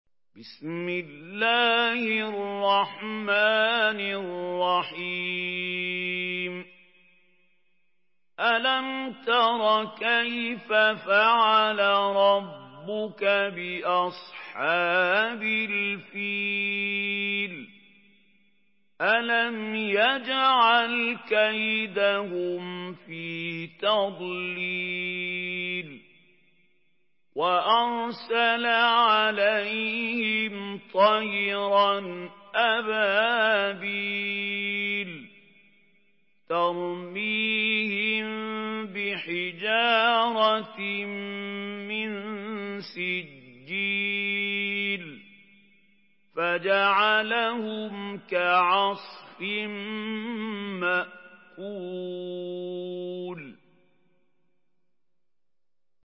Surah আল-ফীল MP3 in the Voice of Mahmoud Khalil Al-Hussary in Hafs Narration
Murattal Hafs An Asim